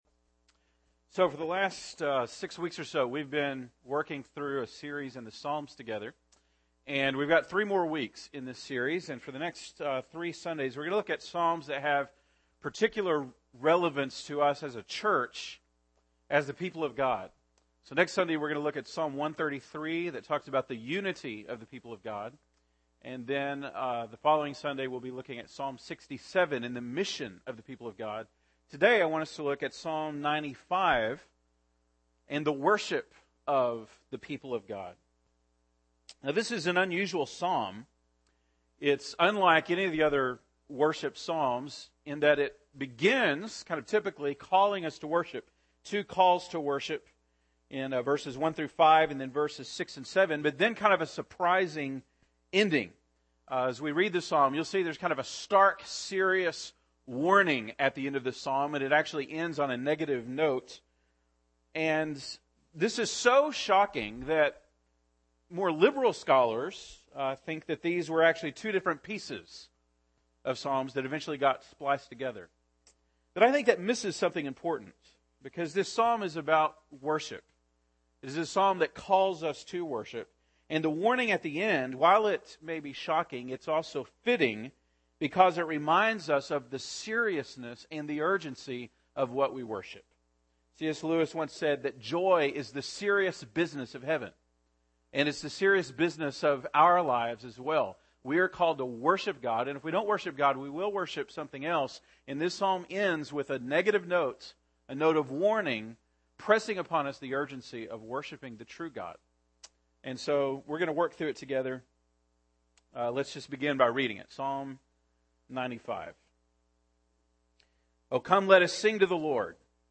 January 6, 2013 (Sunday Morning)